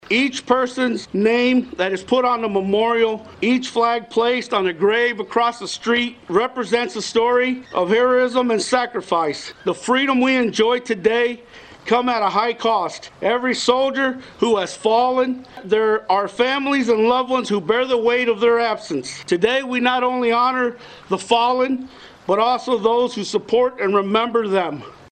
(STURGIS) – A small but enthusiastic crowd came out Monday for the Sturgis Memorial Day Celebration.
The event, held at Oak Lawn Park, featured a welcome address by Sturgis Mayor Frank Perez, who shared that on Memorial Day, we not only honor those who served and died, but the family members of those lost service men and women.